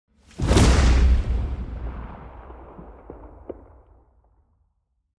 دانلود صدای رعدو برق 15 از ساعد نیوز با لینک مستقیم و کیفیت بالا
جلوه های صوتی